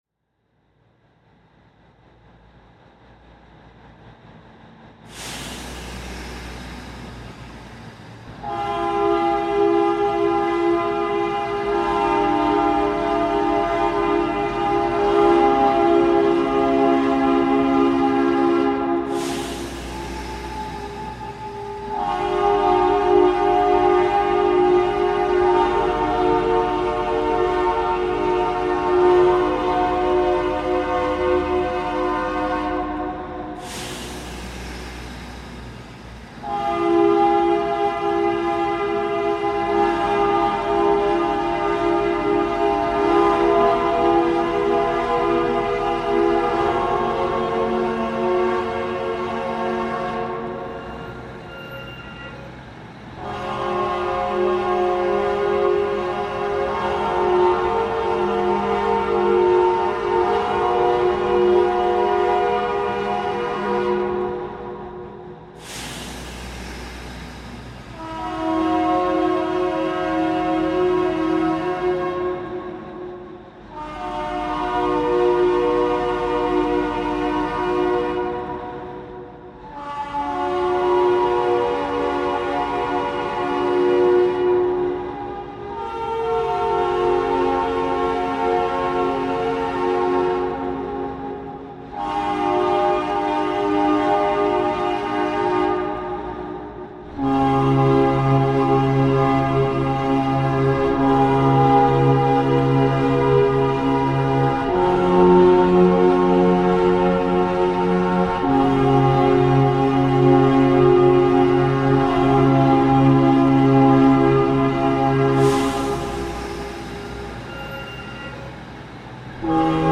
Toy train, Darjeeling reimagined